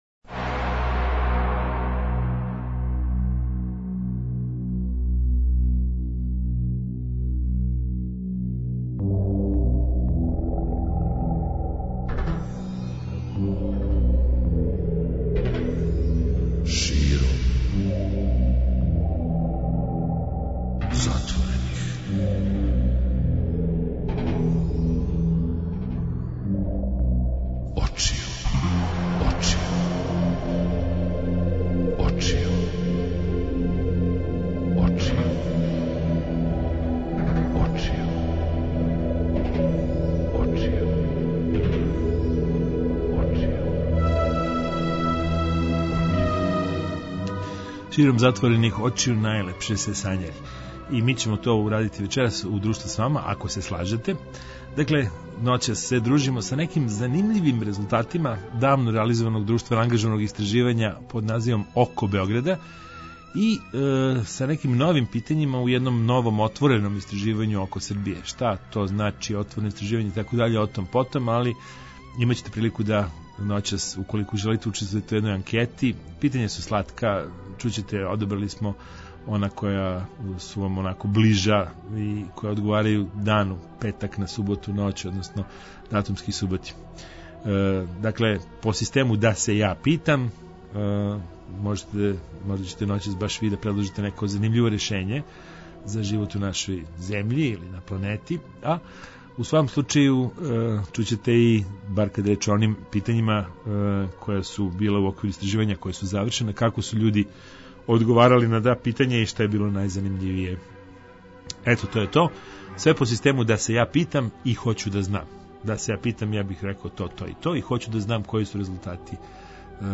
Публика Београда 202 имаће прилику да учествује у анкети путем радија, да одговара на питања, али и да, по систему „да се ја питам", предлаже нека занимљива решења за живот у нашој земљи.